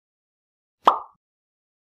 Pop Sound
cartoon